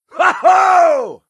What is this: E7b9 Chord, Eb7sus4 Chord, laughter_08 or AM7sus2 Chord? laughter_08